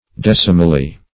decimally - definition of decimally - synonyms, pronunciation, spelling from Free Dictionary Search Result for " decimally" : The Collaborative International Dictionary of English v.0.48: Decimally \Dec"i*mal*ly\, adv. By tens; by means of decimals.